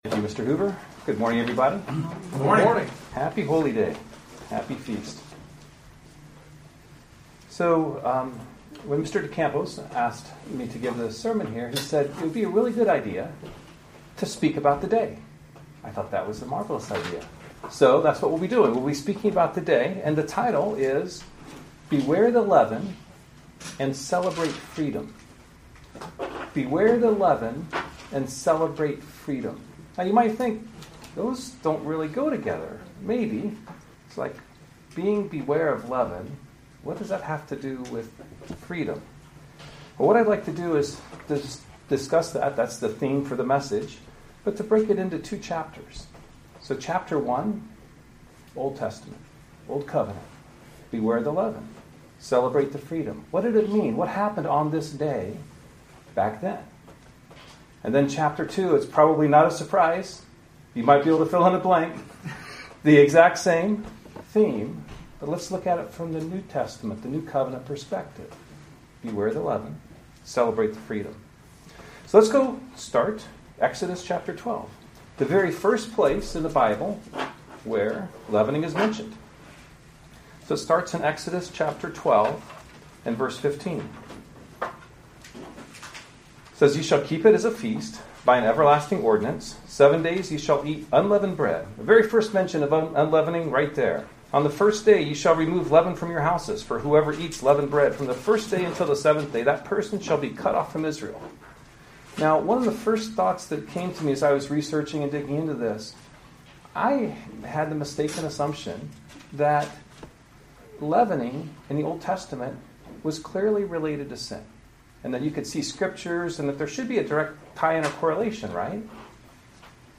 Join us for this eyeopening sermon about the God's Holy Day plan.
Given in Lexington, KY